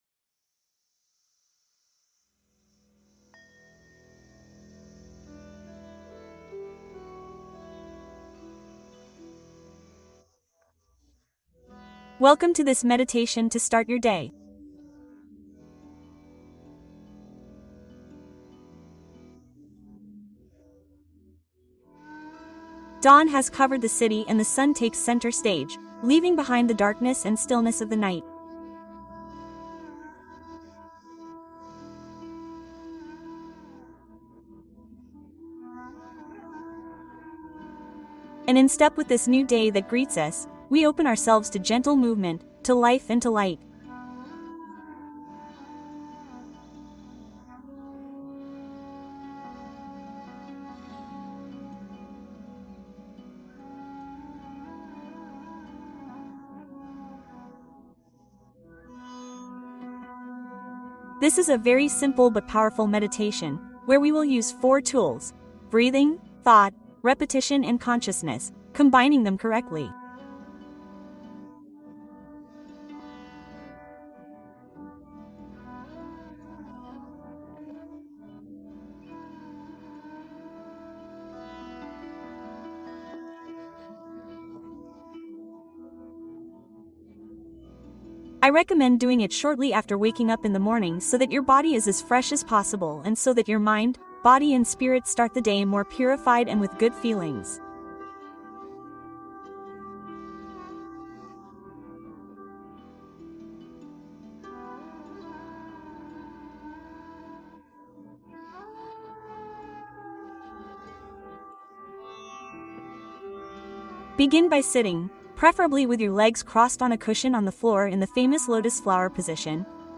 Cuento para dormir Mejora tu sueño y calma tu ansiedad